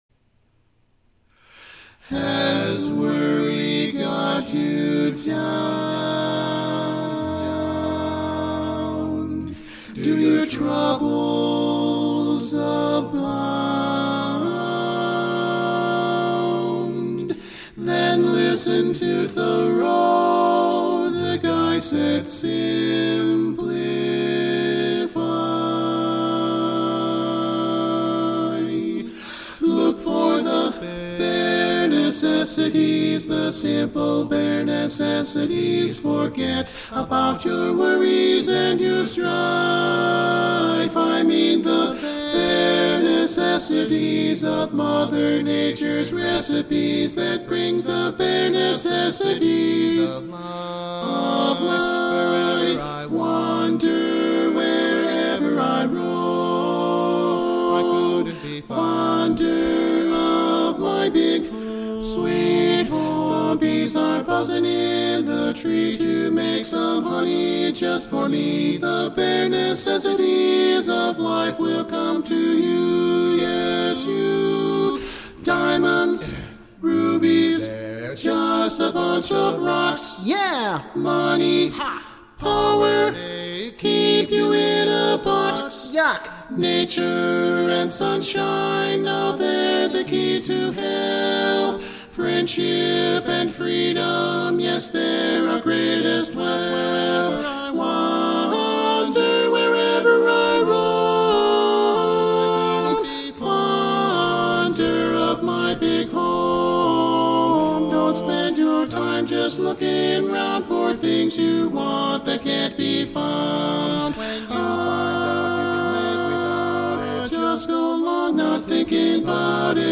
F Major